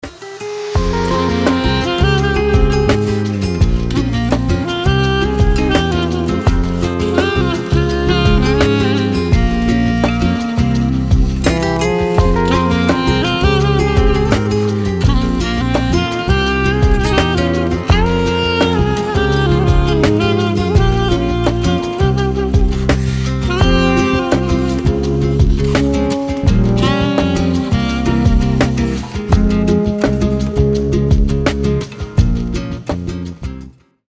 • Качество: 320, Stereo
гитара
без слов
красивая мелодия
Саксофон
New Age
Немного плаксивая осенняя мелодия